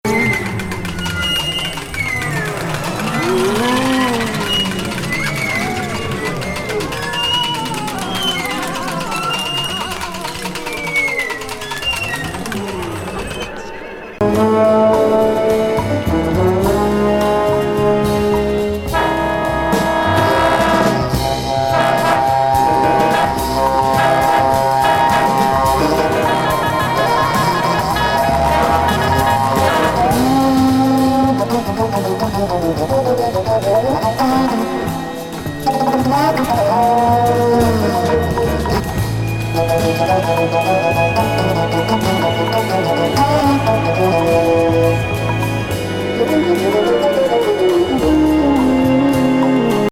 ブラジリアン・ジャズ